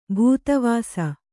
♪ bhūta vāsa